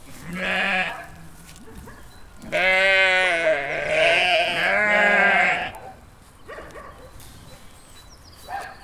• sheeps baaing dog in background.wav
Recorded with a Tascam DR 40.
sheeps_baaing_dog_in_background_nPM.wav